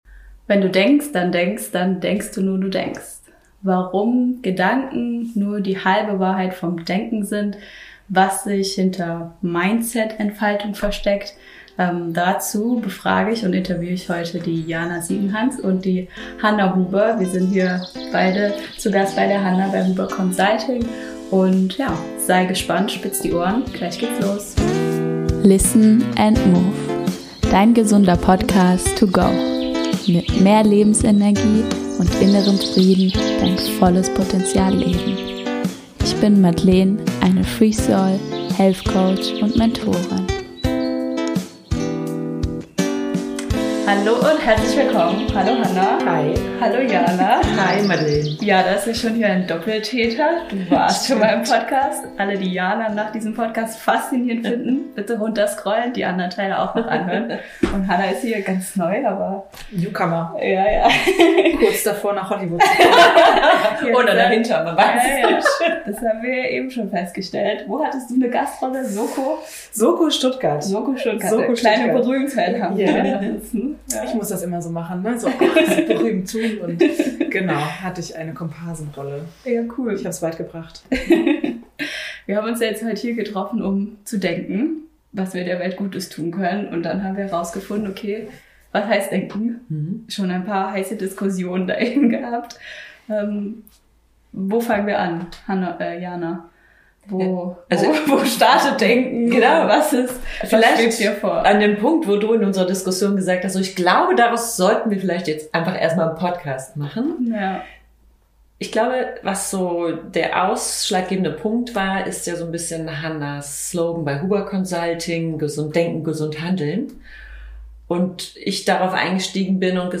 Erfahre in diesem Interview, wie du dein Denken erweitern und eine gesunde Führungskompetenz entwickeln kannst. Wir tauchen ein in Themen wie persönliche Entwicklung, Unternehmenskultur und Kommunikation.